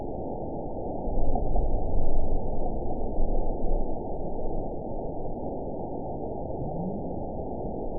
event 917120 date 03/20/23 time 21:26:45 GMT (2 years, 1 month ago) score 9.40 location TSS-AB03 detected by nrw target species NRW annotations +NRW Spectrogram: Frequency (kHz) vs. Time (s) audio not available .wav